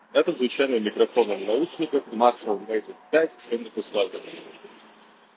Единственное, немного подкачал с отрезанием лишних шумов в шумных условиях, сравнивая с Apple Airpods Pro 2.
В шумных условиях: